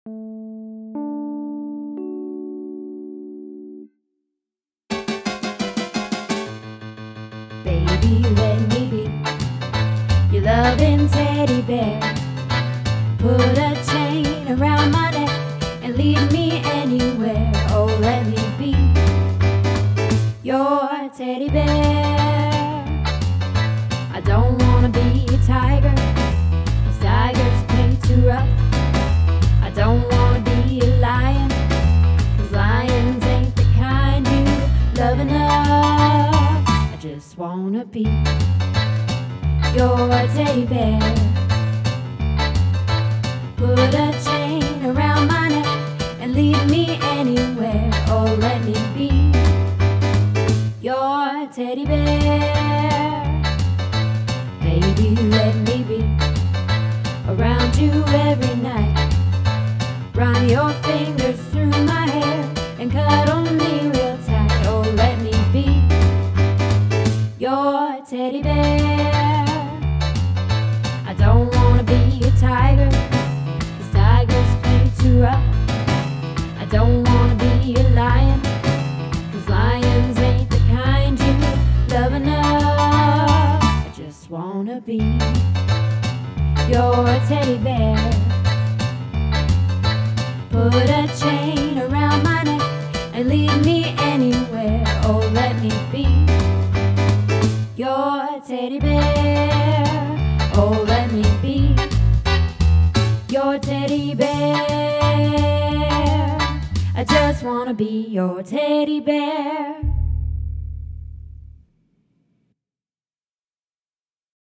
Teddy Bear - Alto